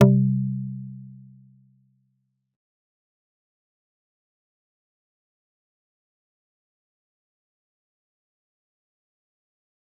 G_Kalimba-C3-mf.wav